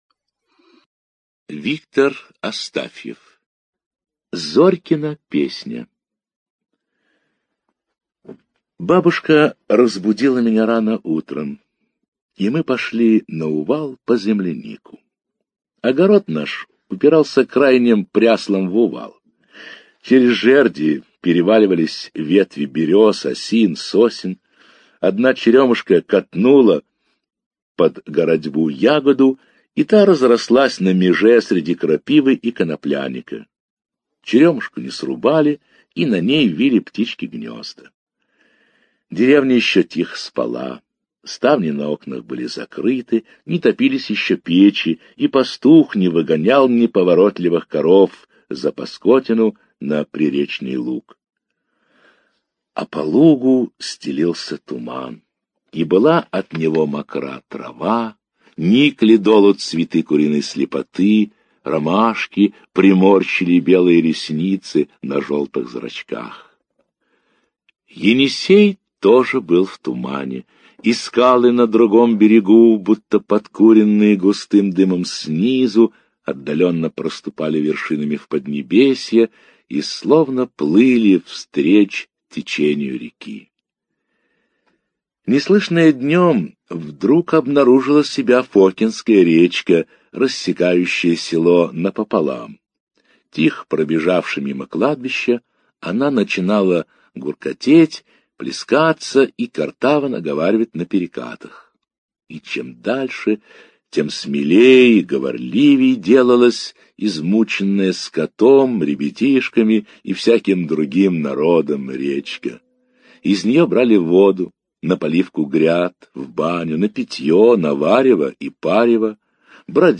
Зорькина песня - аудио рассказ Астафьева - слушать онлайн